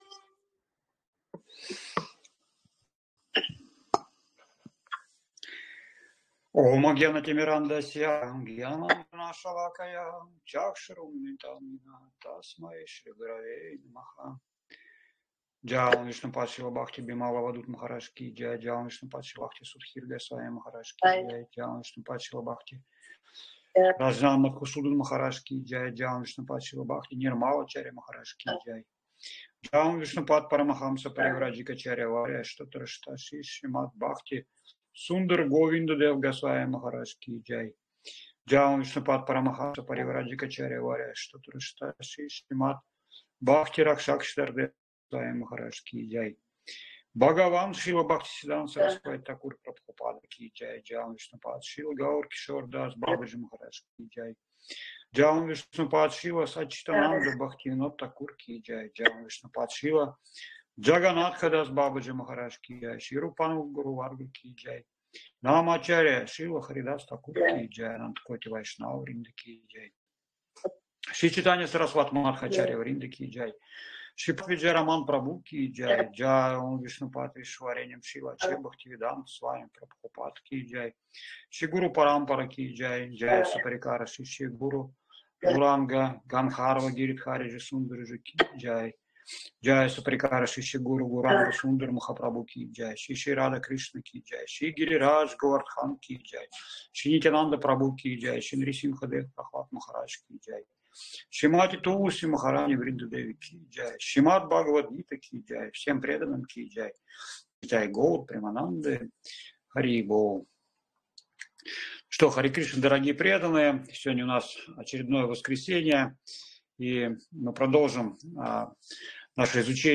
#00:05:00# Молитвы перед чтением Бхагавад-гиты